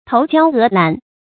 头焦额烂 tóu jiāo é làn
头焦额烂发音
成语注音ㄊㄡˊ ㄐㄧㄠ ㄜˊ ㄌㄢˋ